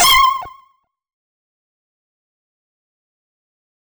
rainbow_laser